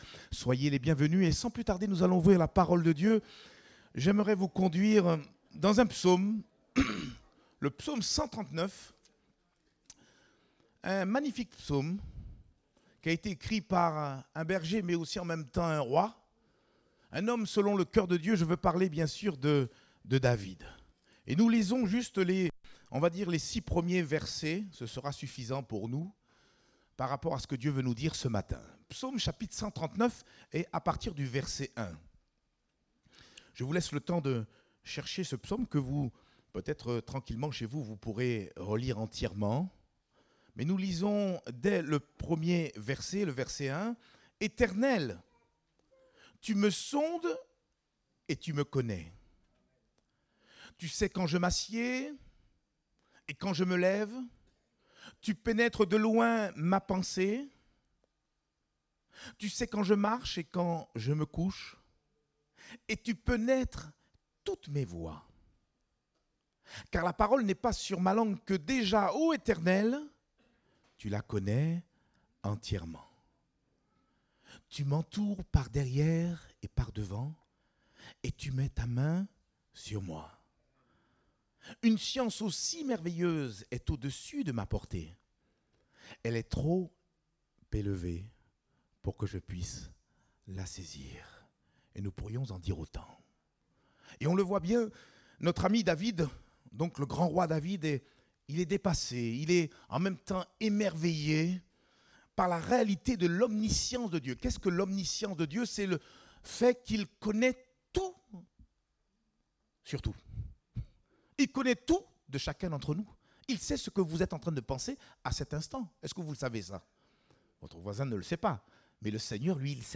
Date : 20 mars 2022 (Culte Dominical)